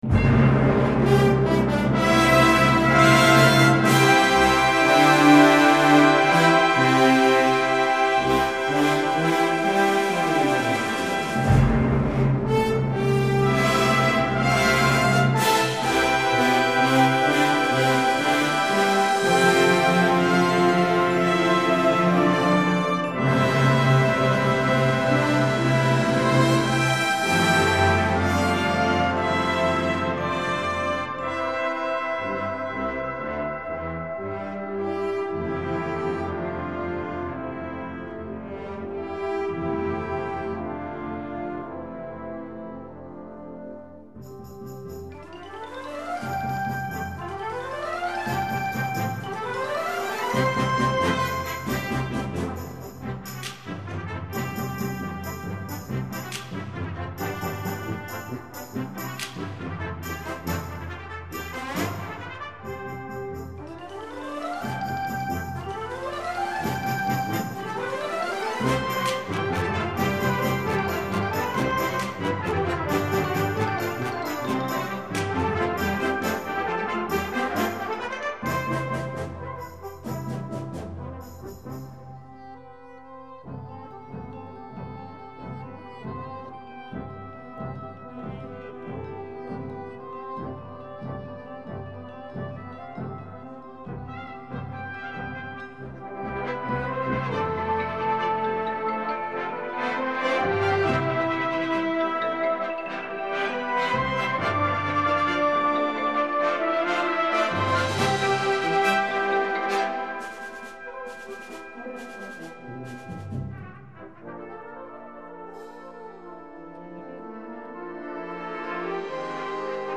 Répertoire pour Harmonie/fanfare - Fanfare